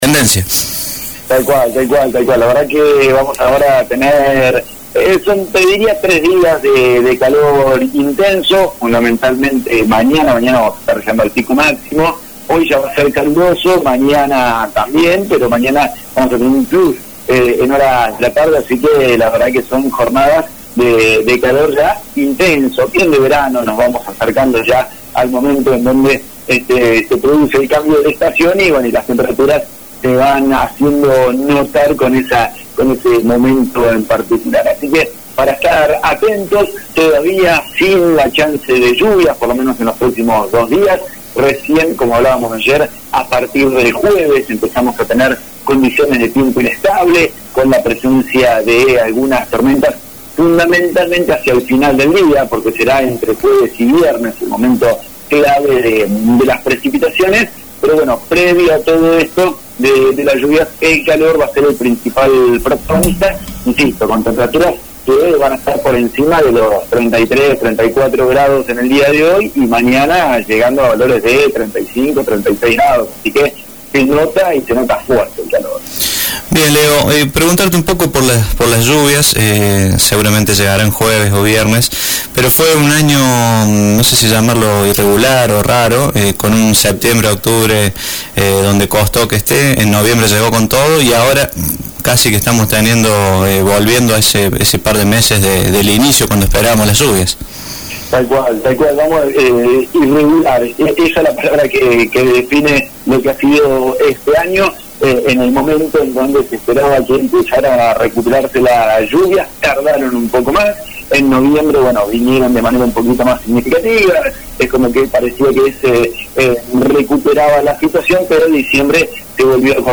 el pronóstico del tiempo para la jornada de hoy